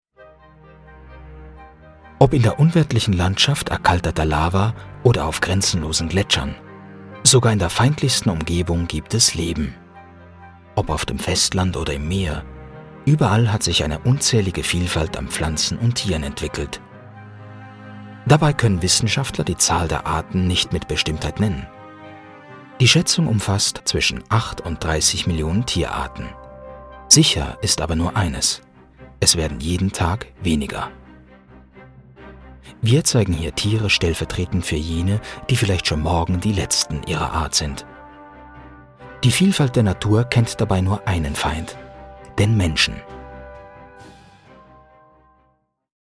Grosse Bandbreite und Wandelbarkeit.
Der Aufnahme- und Regieraum ist zu 100% schalldicht.
Sprechprobe: eLearning (Muttersprache):